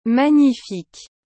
Como pronunciar magnifique corretamente?
Em francês, magnifique é dito como /maɲiˈfik/.
• O “gn” tem som parecido com o nosso “nh”, então nada de dizer “mag-nifique”!
• O “i” precisa ser bem claro e marcado.
• O “que” no final tem som de “k”, tipo em “música”.